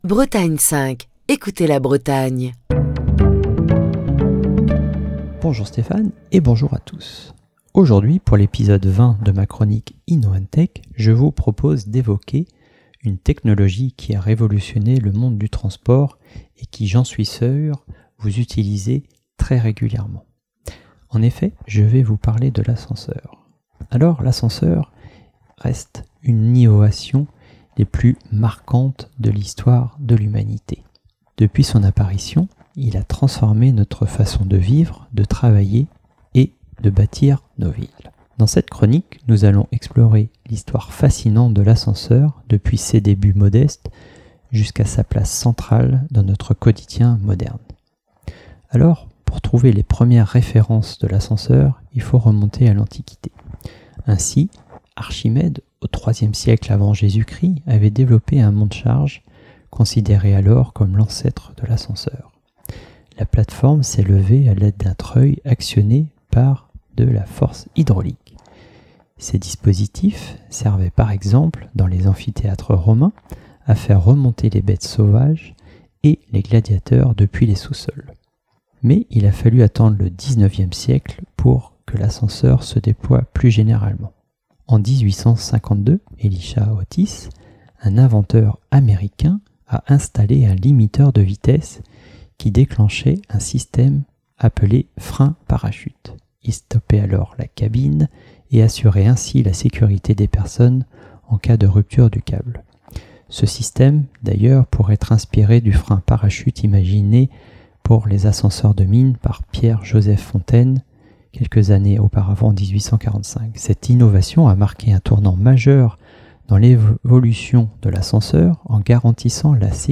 Chronique du 14 juin 2023. L'outil est bien pratique et pourtant certaines personnes hésitent à l'emprunter, parfois par crainte d'une panne ou encore de rester bloqué entre deux étages... et malgré tout l'ascenseur reste le moyen de transport le plus sûr au monde. Il est donc question des ascenseurs ce matin dans Inno & Tech.